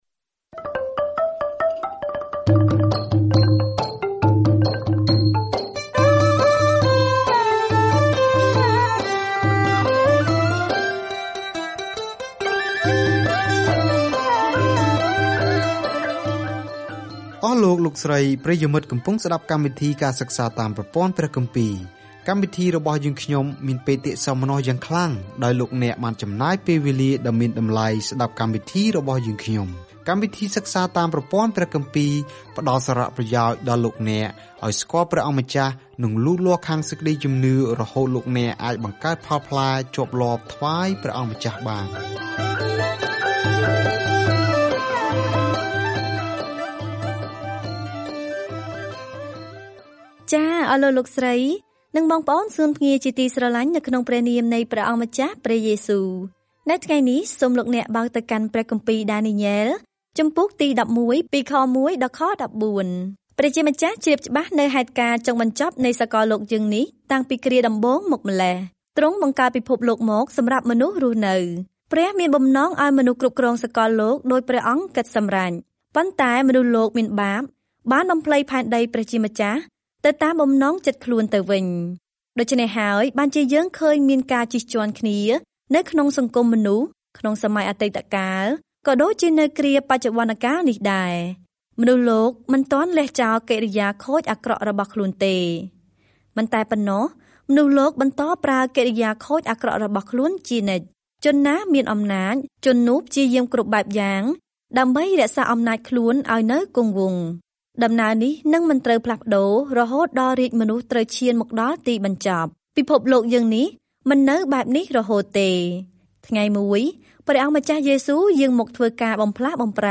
សៀវភៅដានីយ៉ែលគឺជាសៀវភៅជីវប្រវត្តិរបស់បុរសម្នាក់ដែលបានជឿព្រះ និងជាទស្សនវិស័យព្យាករណ៍អំពីអ្នកដែលនឹងគ្រប់គ្រងពិភពលោកនៅទីបំផុត។ ការធ្វើដំណើរជារៀងរាល់ថ្ងៃតាមរយៈដានីយ៉ែល នៅពេលអ្នកស្តាប់ការសិក្សាជាសំឡេង ហើយអានខគម្ពីរដែលជ្រើសរើសពីព្រះបន្ទូលរបស់ព្រះ។